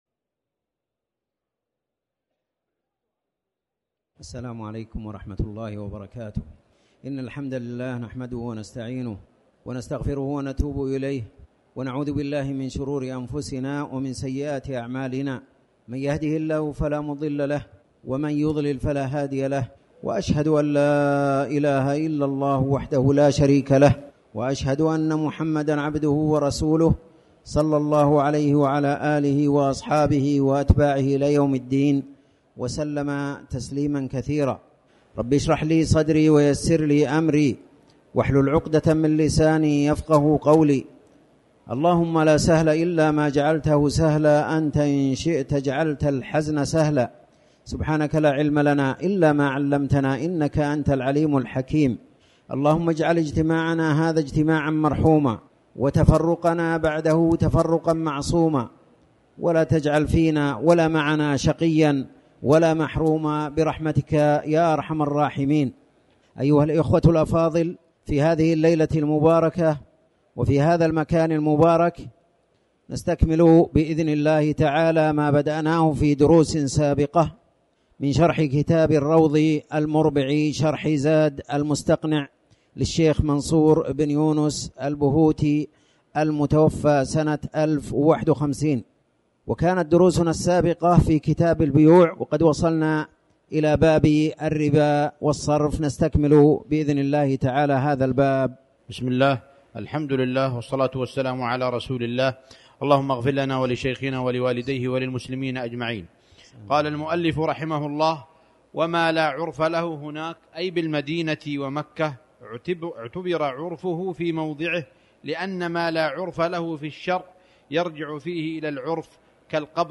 تاريخ النشر ٣٠ جمادى الأولى ١٤٤٠ هـ المكان: المسجد الحرام الشيخ